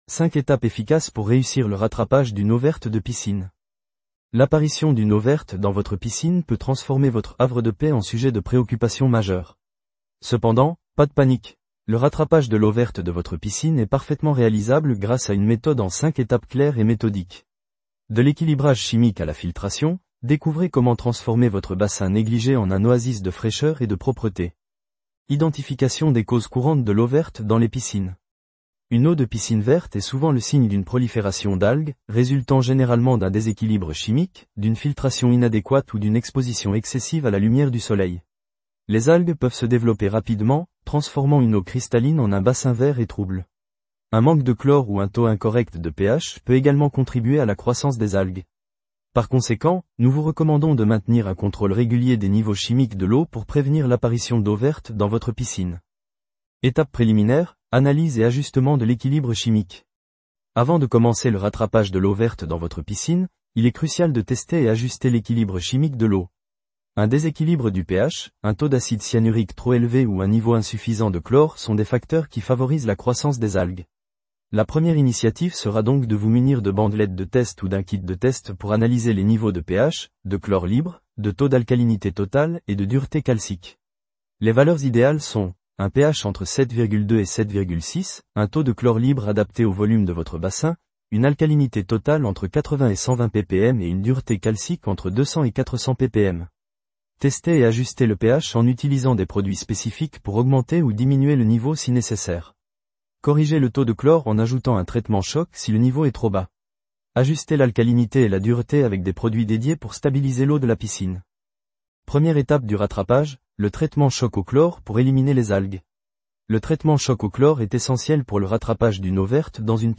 Retranscription IA :